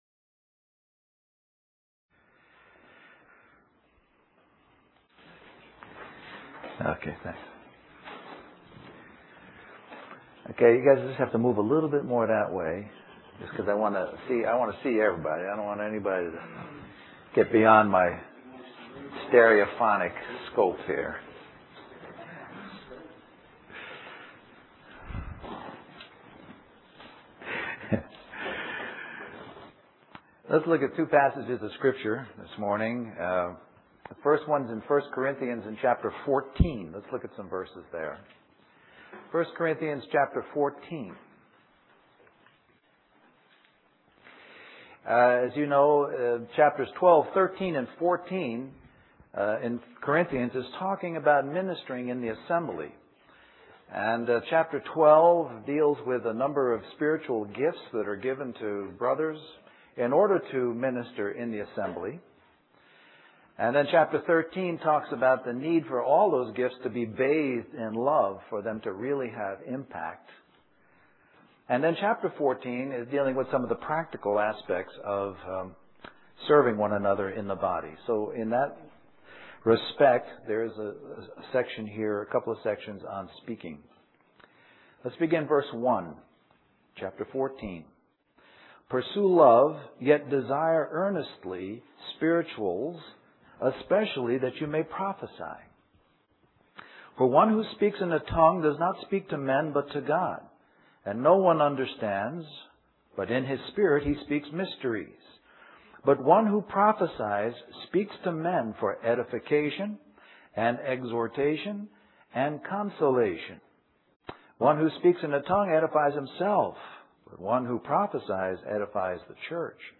US Stream or download mp3 Summary The assembly in Richmond held a half-day seminar on the Ministry of God's word.